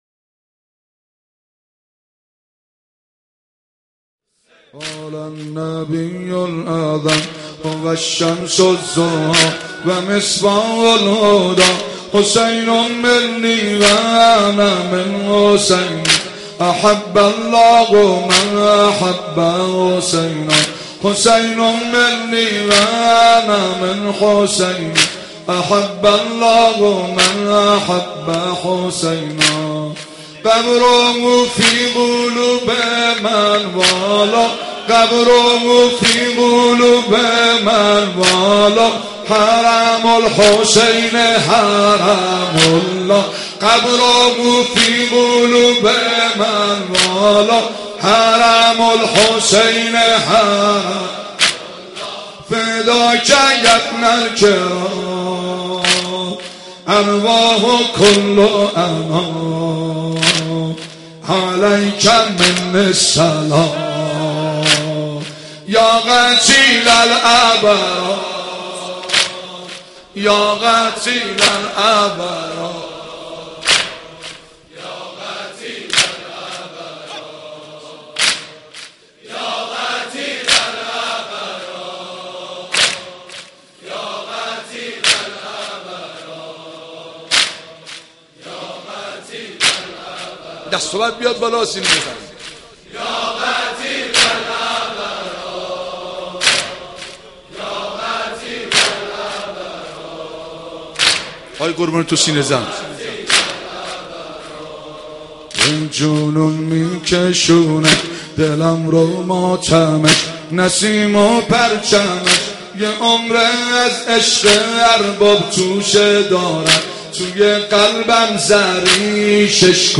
محرم 88 - سینه زنی 8
محرم-88---سینه-زنی-8